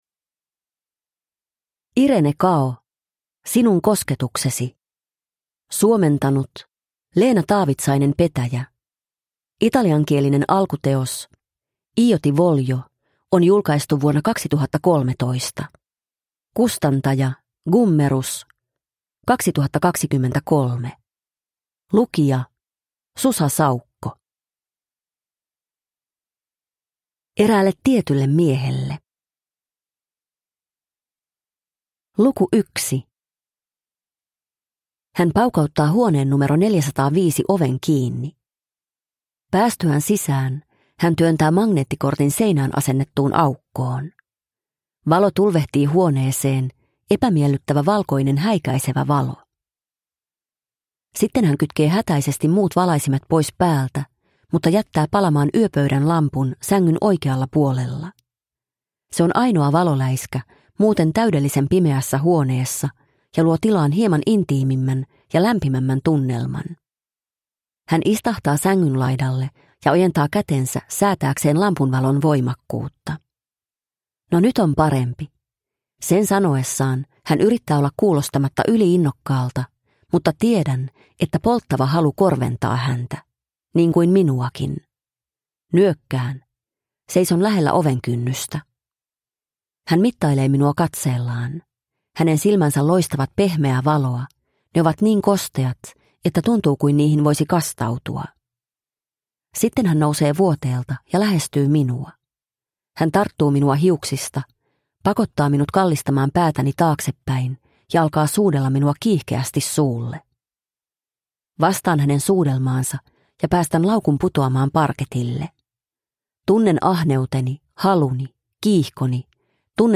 Sinun kosketuksesi – Ljudbok – Laddas ner